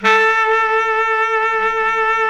SAX B.SAX 0F.wav